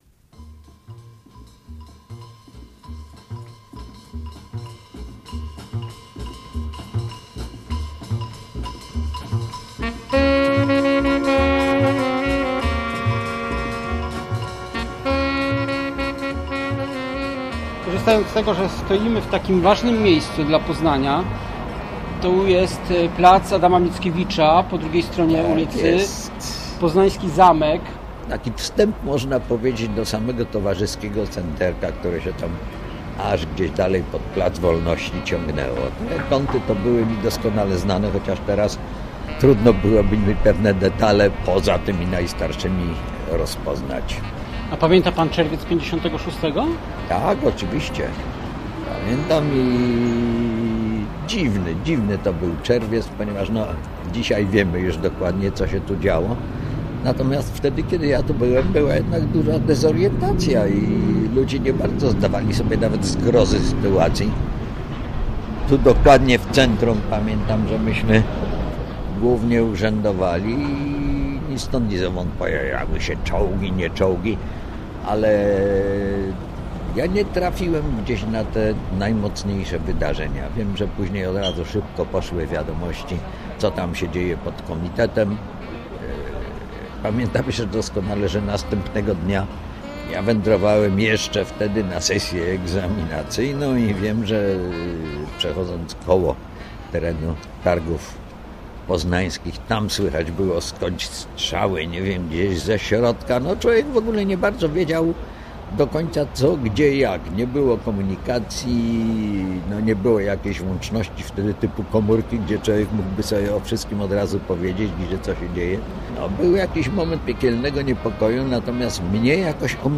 Będziemy mieli jazz - reportaż o Janie Ptaszynie-Wróblewskim